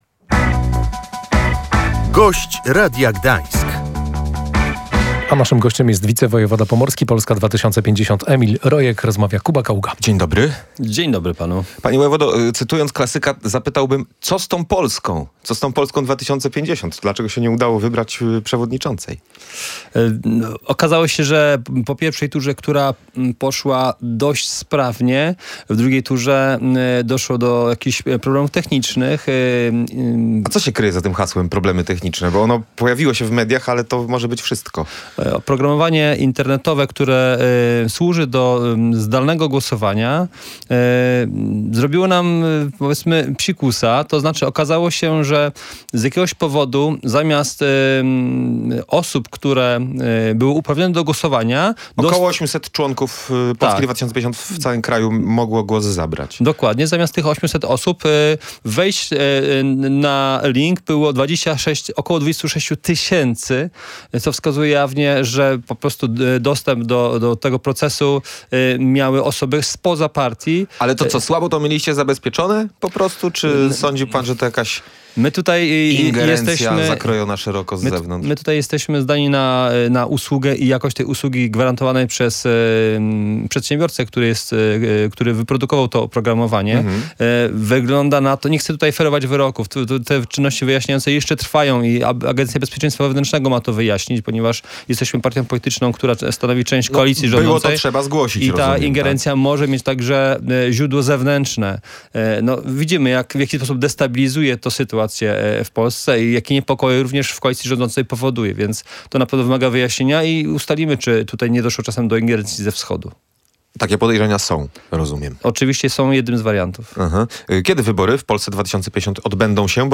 Wicewojewoda pomorski Emil Rojek mówił w Radiu Gdańsk, że w drugiej turze wyborów wewnątrzpartyjnych wejść na platformę było więcej, niż jest członków partii.